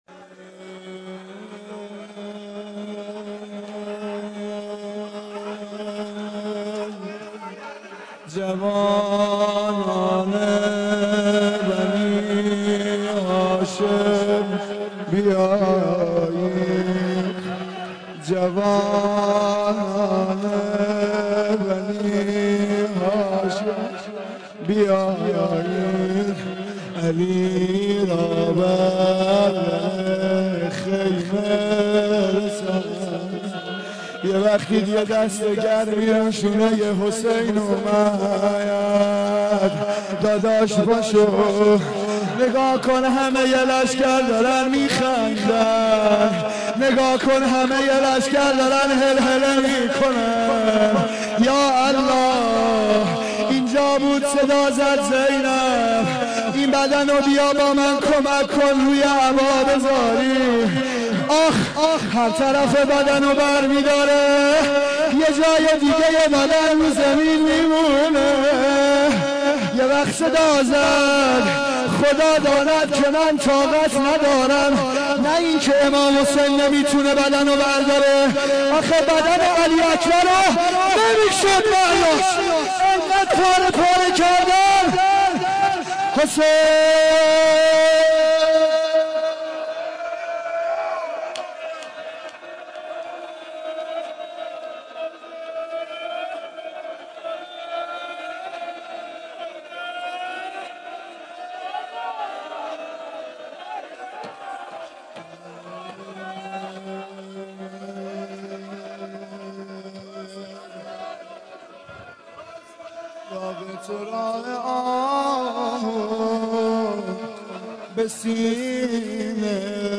روضه شب هشتم محرم 1391